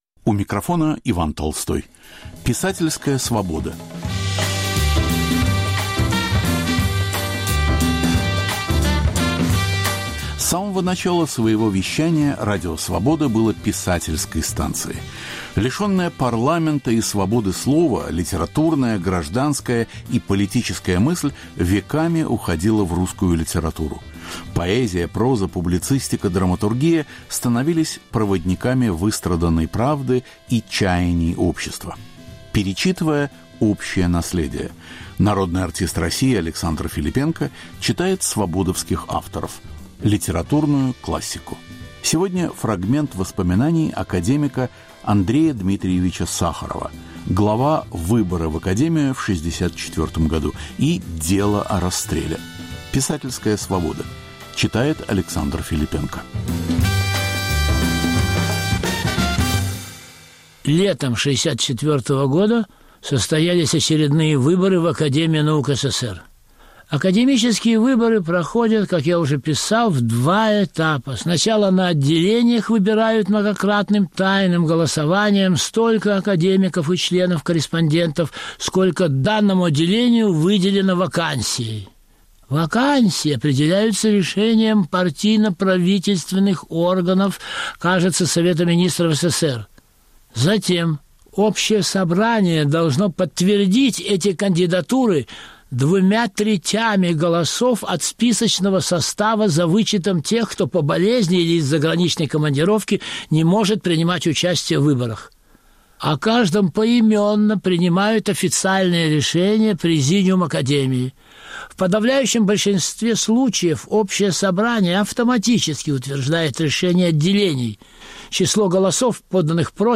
Писательская Свобода. Александр Филиппенко читает свободовских авторов - академика Сахарова и Андрея Амальрика.
Народный артист России Александр Филиппенко раз в месяц читает писателей "Свободы". В этот раз - глава из воспоминаний академика Сахарова и отрывок из записок историка и публициста Андрея Амальрика.